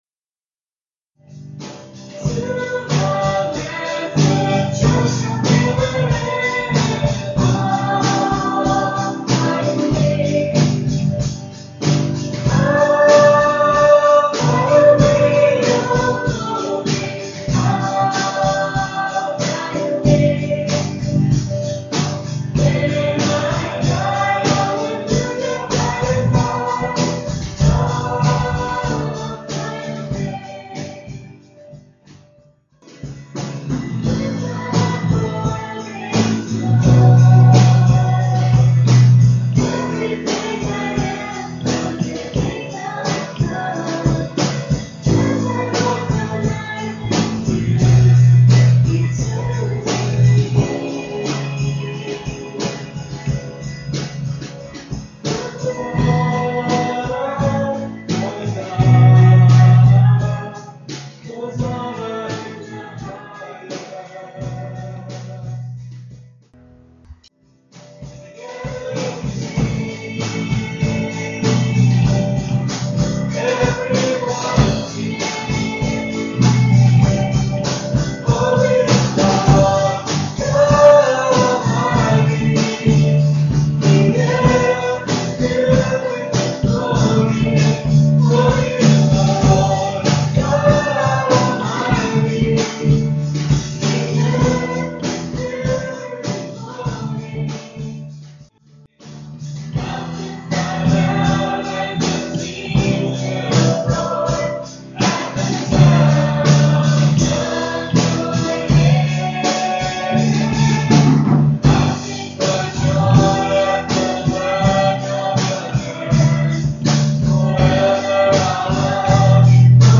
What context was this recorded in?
at Ewa Beach Baptist Church